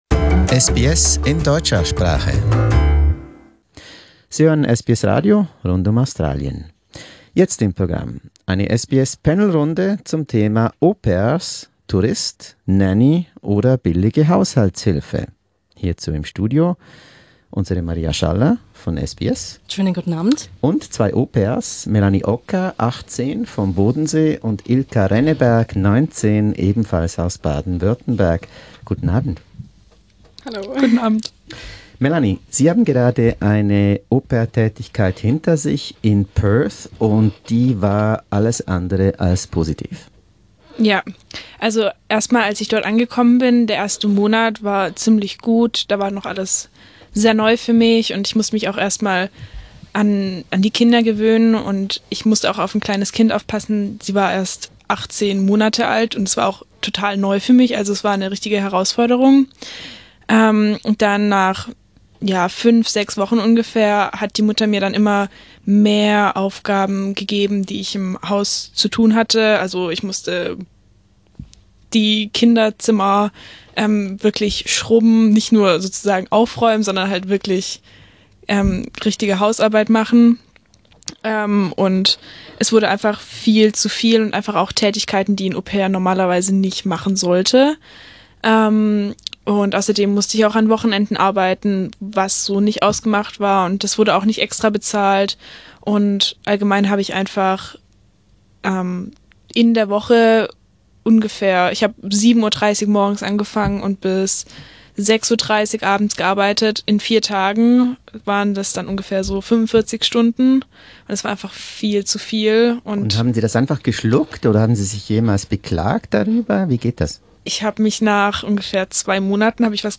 To find out more, we invited German Au Pairs to our SBS studio.